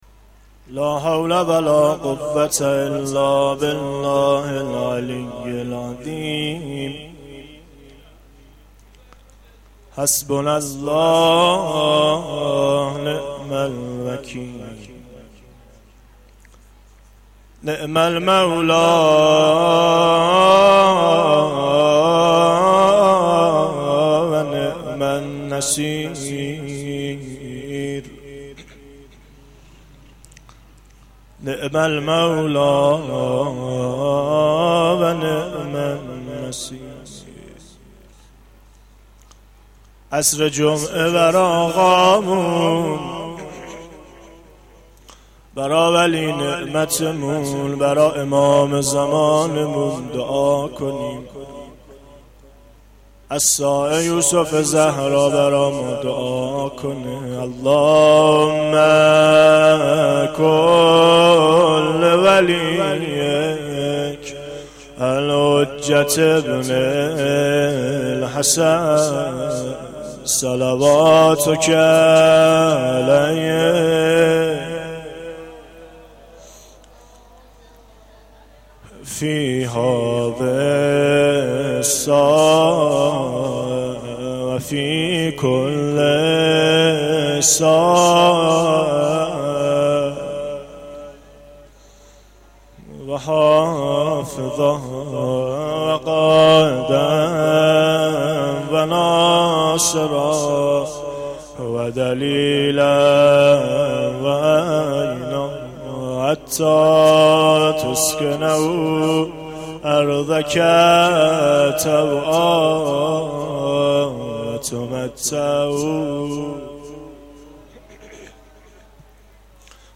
مناجات3
مداحی
مناجات-2.mp3